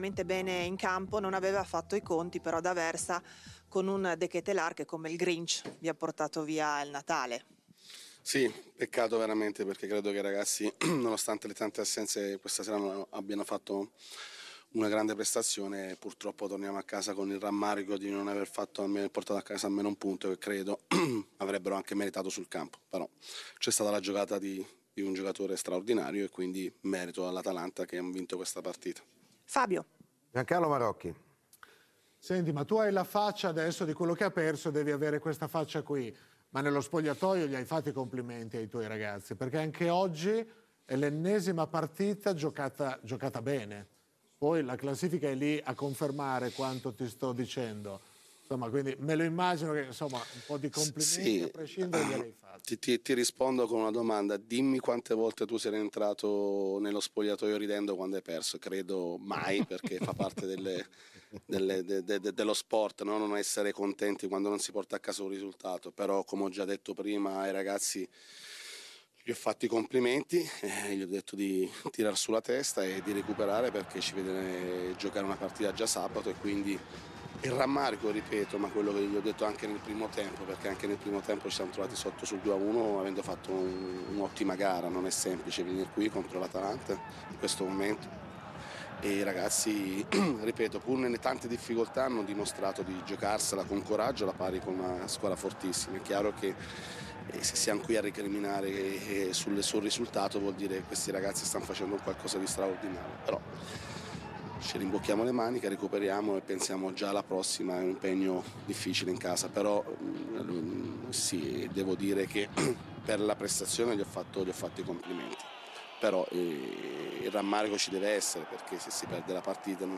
In conferenza stampa poi Il tecnico dell'Empoli, Roberto D'Aversa, ha commentato la sconfitta di 3-2 contro l'Atalanta, evidenziando le prestazioni positive dei giovani Colombo ed Esposito.